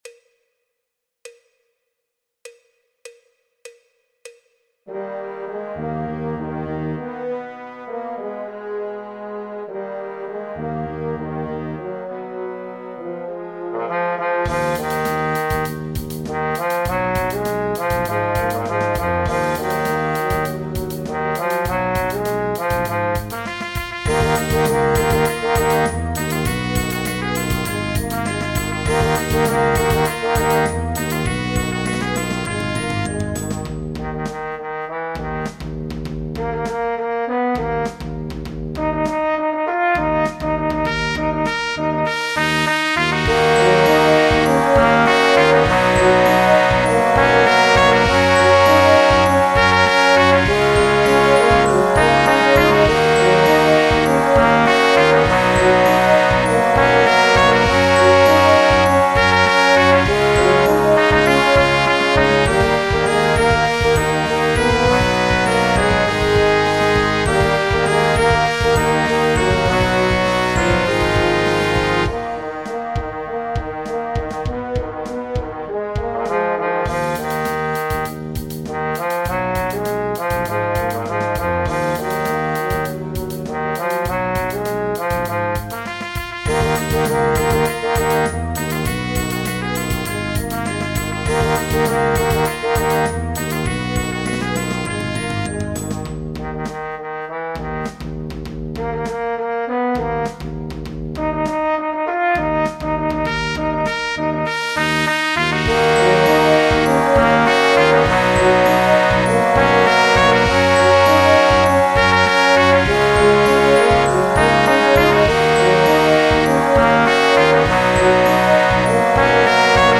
Rhythmisch ein wenig challenging aber gut machbar.